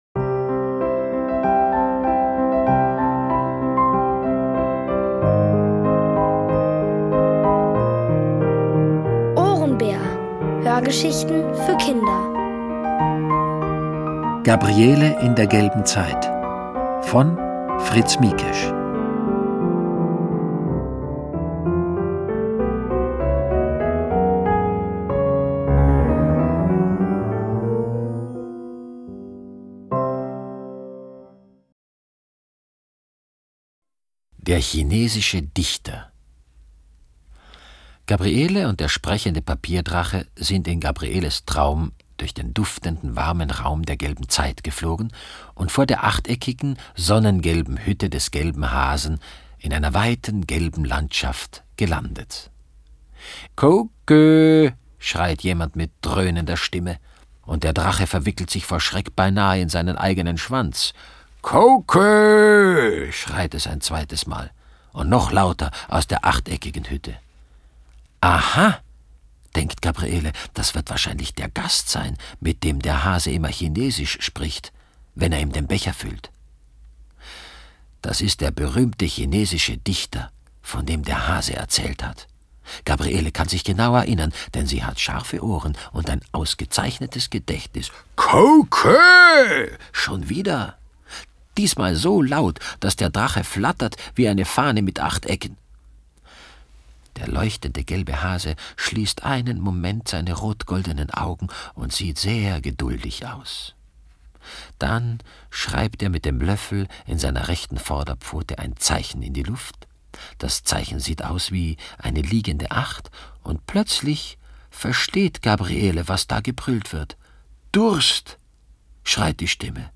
OHRENBÄR-Hörgeschichte: Gabriele in der gelben Zeit (Folge 5 von 7)
Es liest: Peter Simonischek.